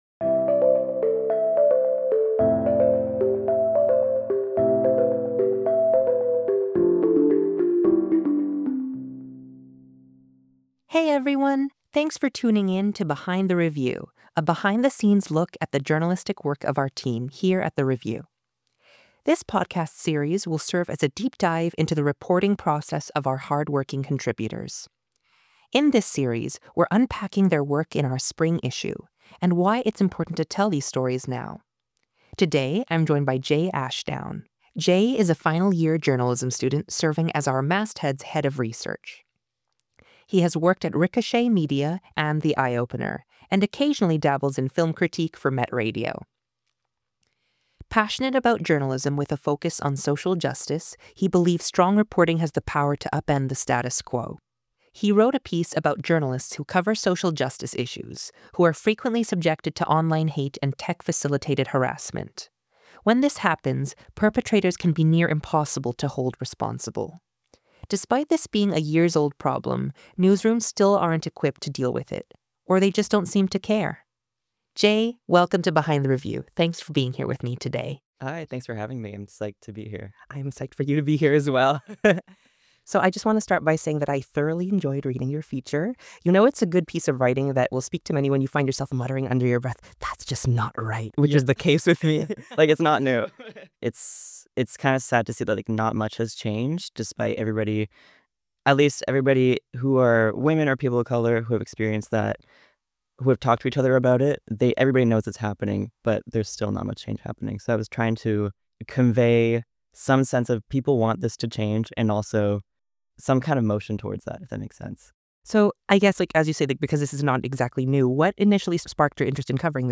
Peaceful Podcast Intro
Chillout Podcast Intro